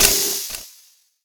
door_steam.wav